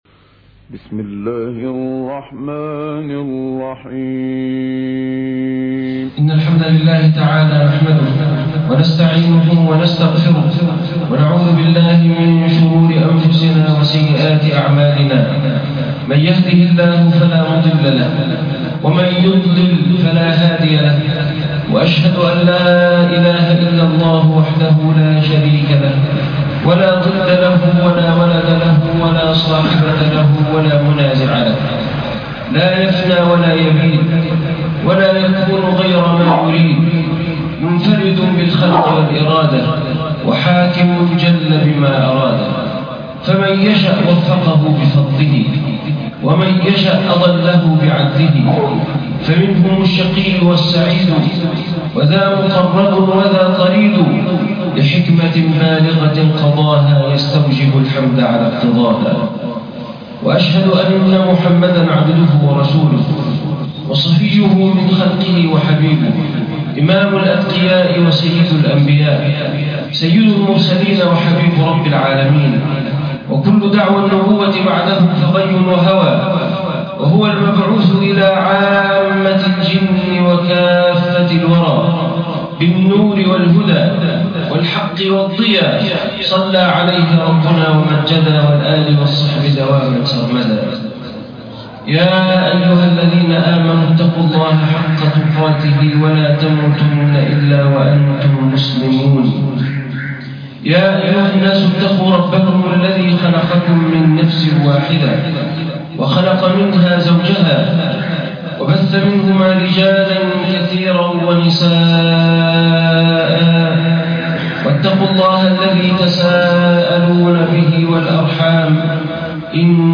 مراتب الناس | خطبة جمعة